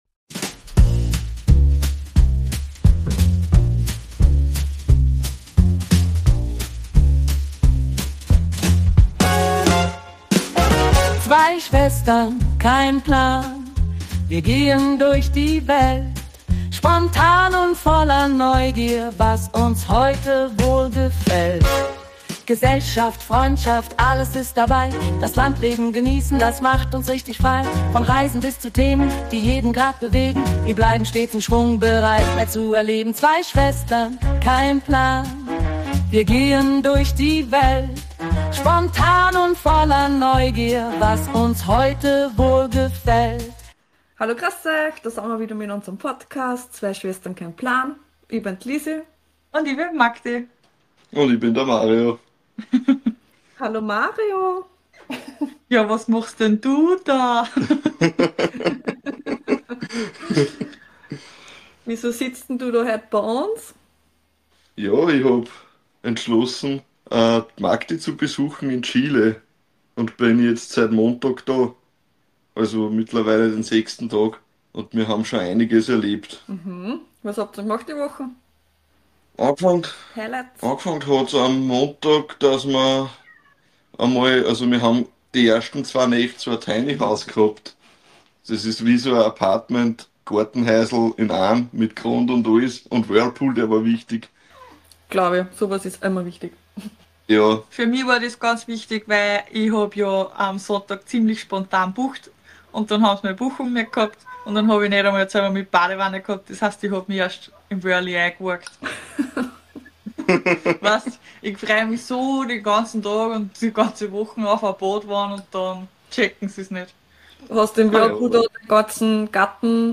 So telefonieren wir 1x die Woche und ihr dürft mitlauschen, was uns in der vergangenen Woche beschäftigt hat.